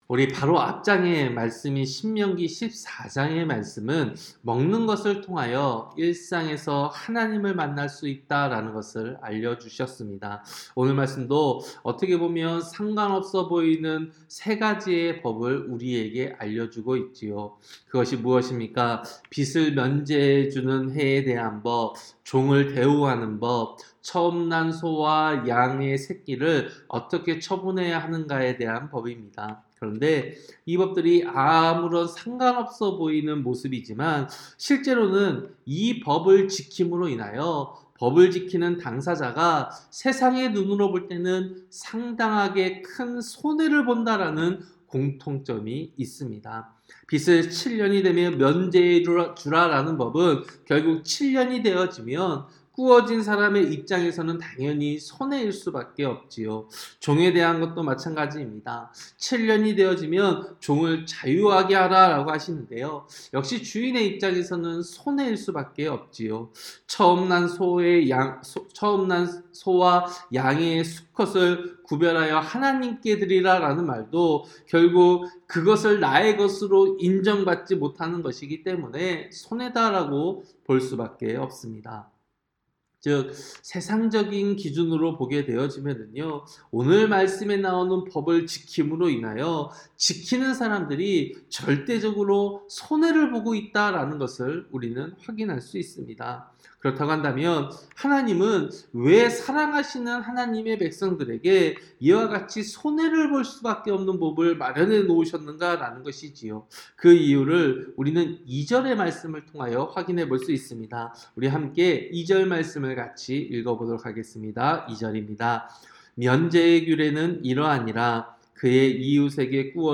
새벽설교-신명기 15장